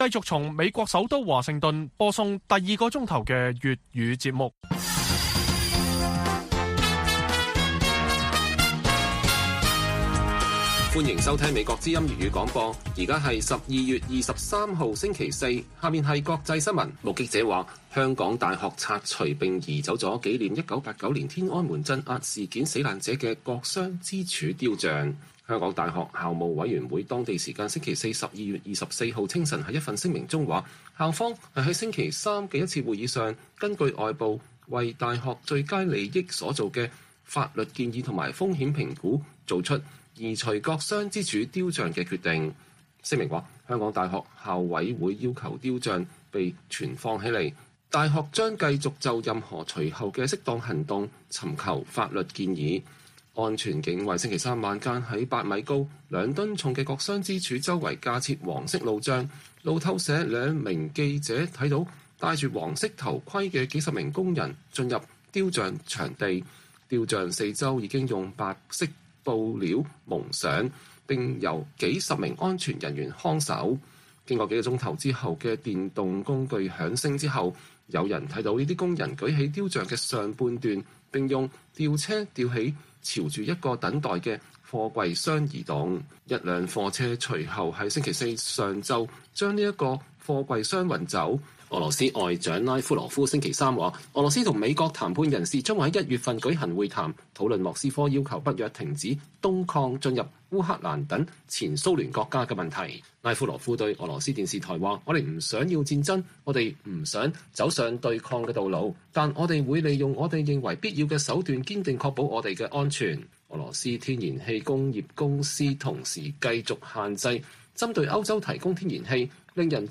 粵語新聞 晚上10-11點: 香港特首林鄭月娥任內最後一次上京述職